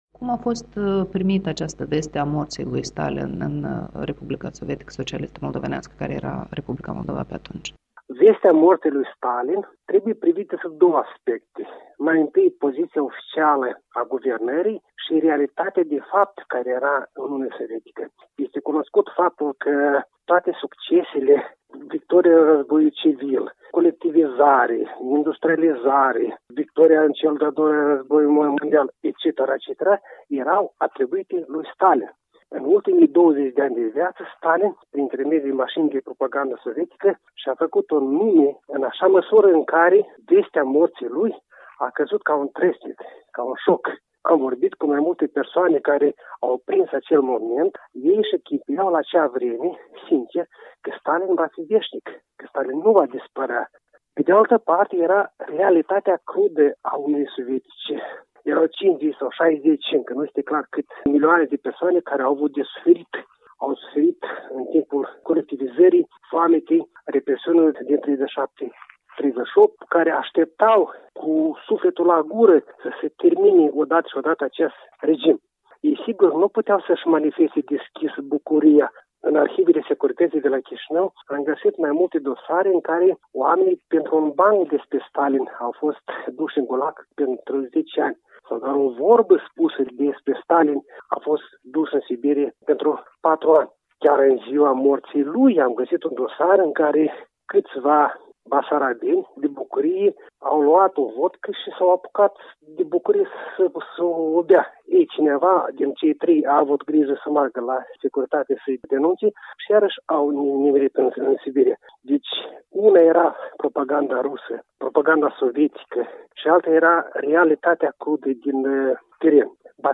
O discuție cu istoricul de la Chișinău la împlinirea a 65 de ani de la moartea lui Stalin.